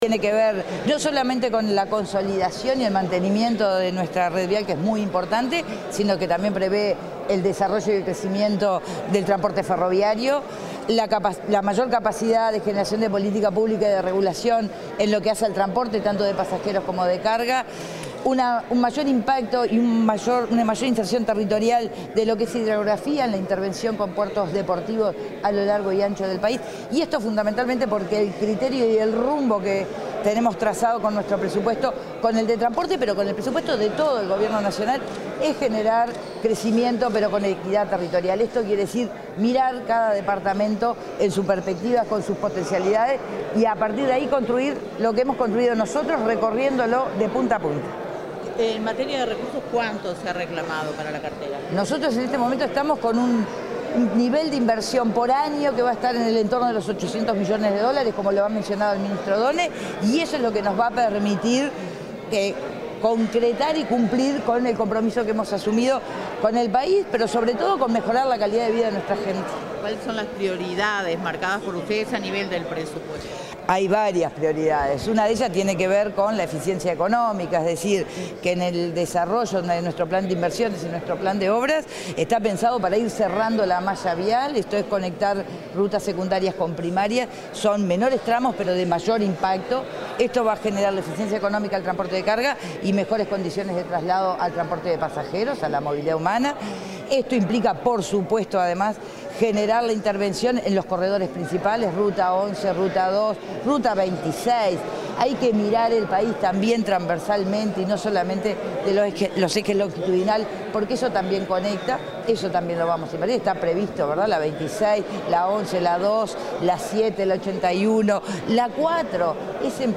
Declaraciones de la ministra de Transporte y Obras Públicas, Lucía Etcheverry